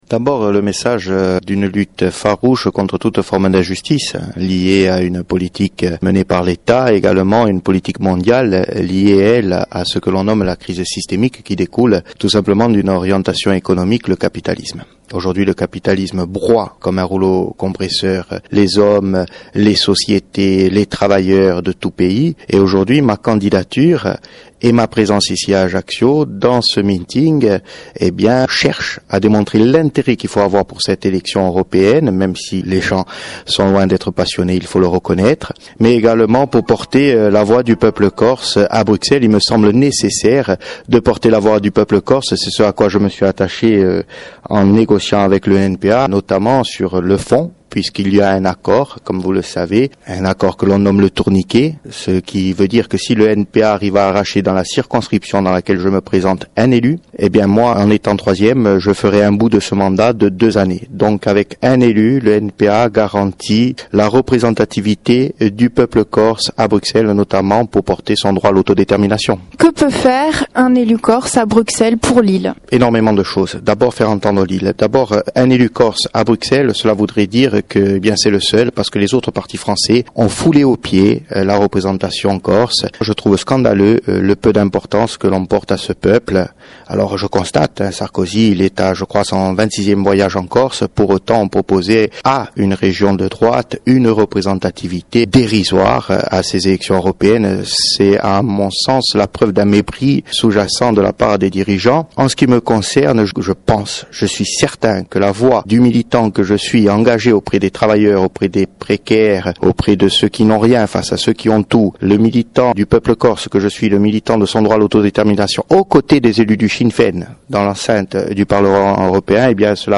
Entretien diffusé sur la radio Alta Frequenza, le 21 mai 2009.